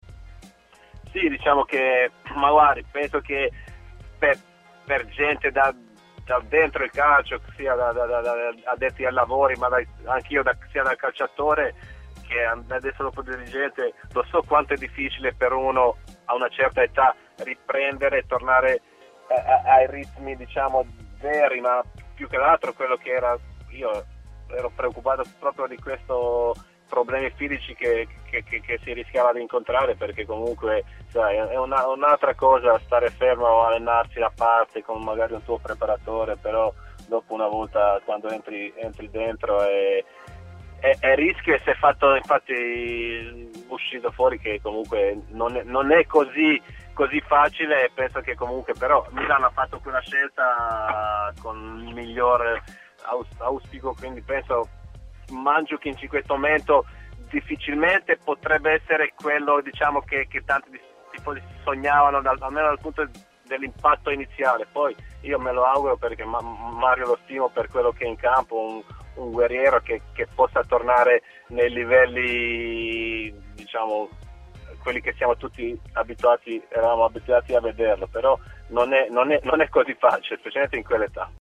Sasa Bjelanovic, intervenuto a TMW Radio, ha detto la sua su Mario Mandzukic e i problemi fisici che stanno caratterizzando la sua prima parte di avventura in rossonero: "So quanto è difficile a una certa età riprendere i ritmi veri, la cosa che più mi avrebbe preoccupato erano proprio questi rischi di problematiche fisiche.